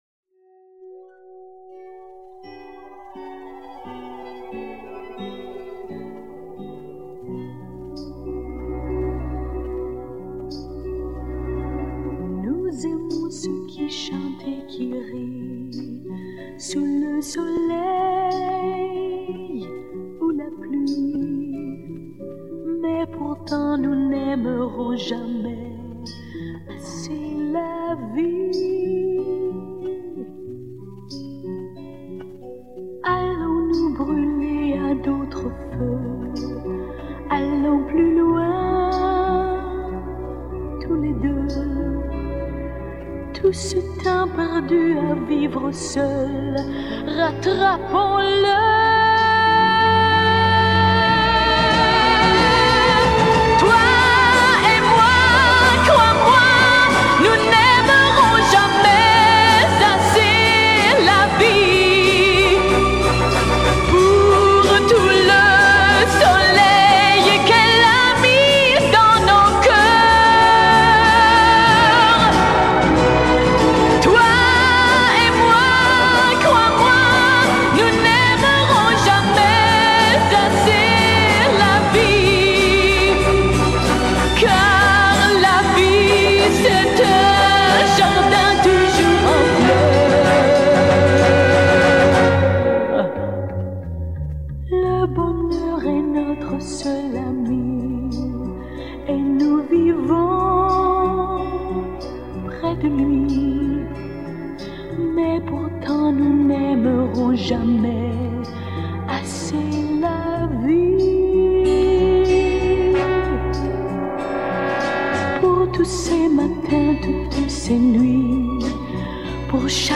Почистил как получилось.